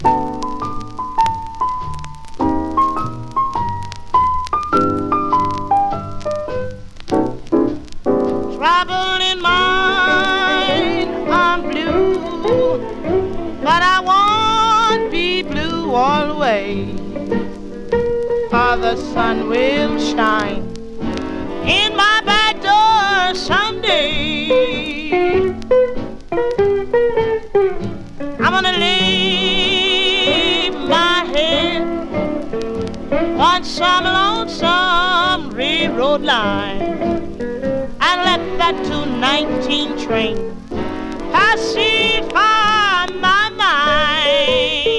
Jazz, Blues, Jump Blues　USA　12inchレコード　33rpm　Mono